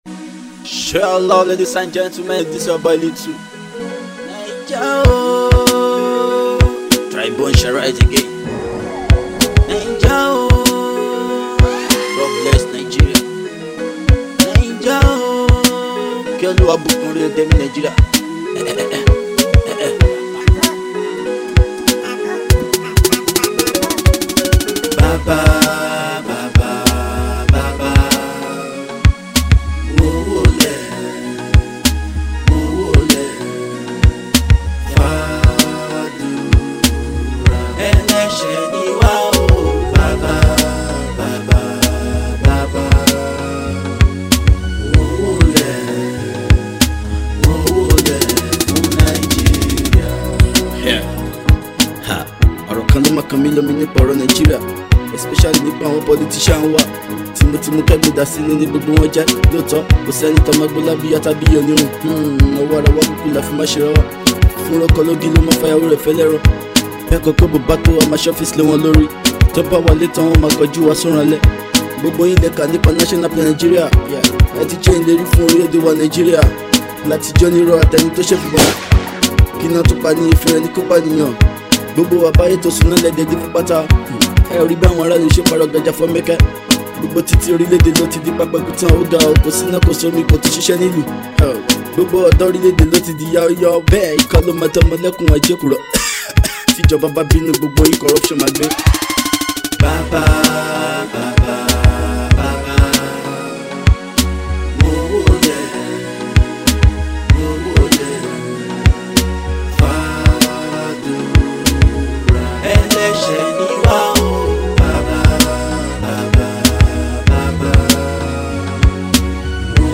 Fast rising Afro singer